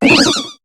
Cri de Babimanta dans Pokémon HOME.